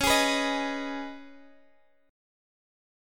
Db7sus2 chord